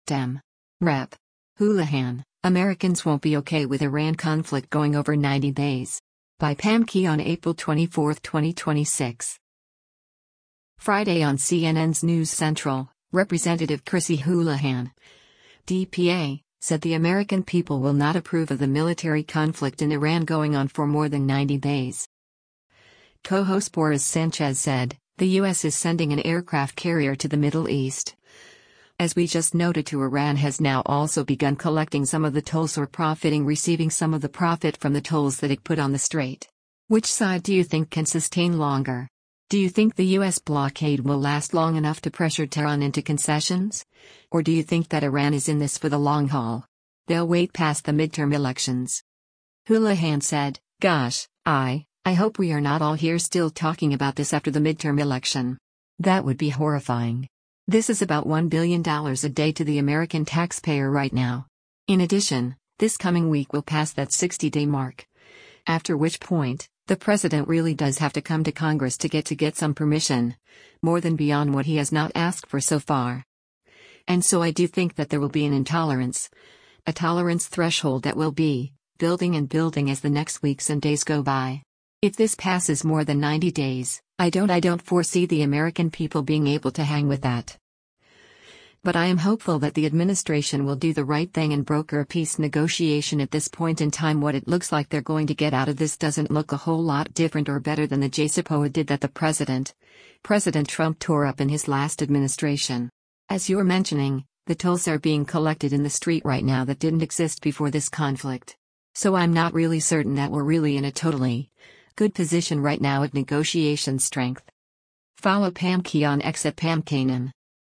Friday on CNN’s “News Central,” Rep. Chrissy Houlahan (D-PA) said the American people will not approve of the military conflict in Iran going on for more than 90 days.